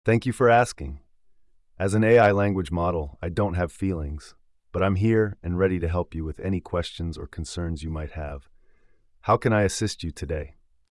TTS，TEXT TO SPEECH,是作为语音输出模型。
所以如果整个过程体验流畅，应该可以给人一种和真人对话感觉，毕竟回复内容是人工智能生成的，然后通过非常逼真语音说出来。
首先这声音真的不太听得出来不是真人，其次语气、停顿等都和真人无异！